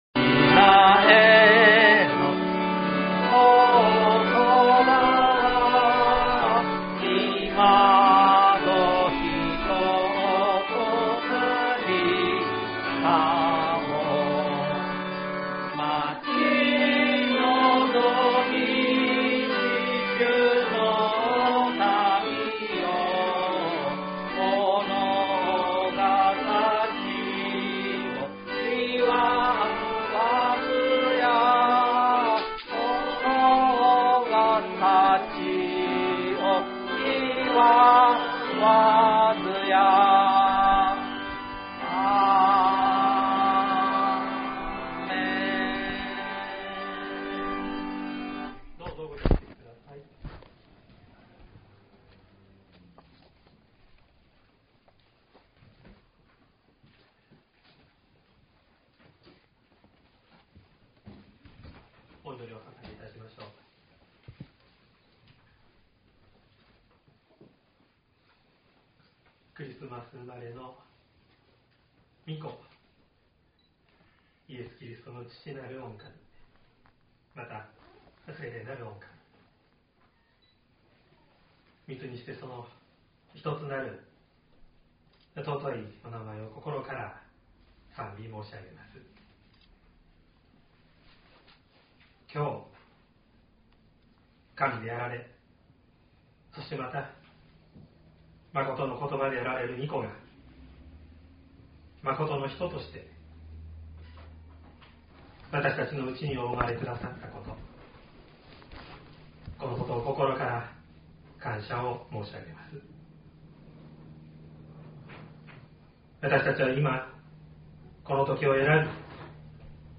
2023年12月24日朝の礼拝「イエスの誕生」西谷教会
説教アーカイブ。
音声ファイル 礼拝説教を録音した音声ファイルを公開しています。